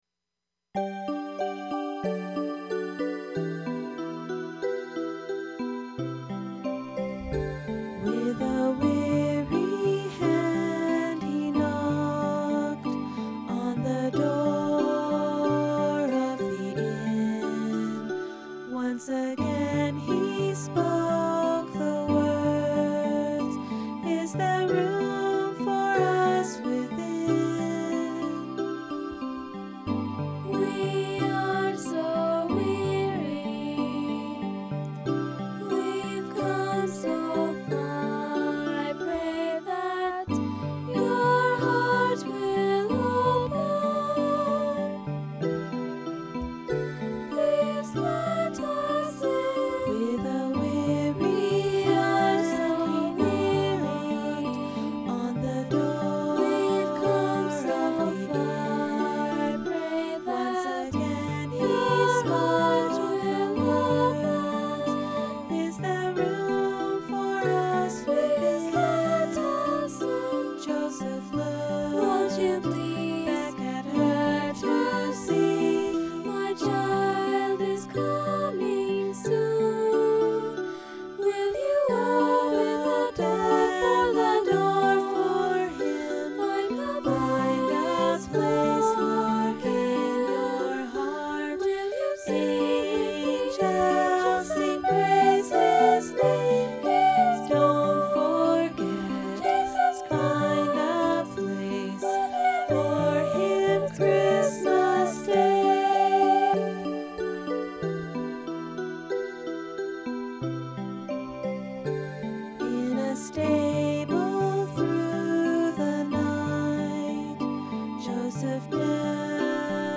This song was written for a Christmas program. It's a duet.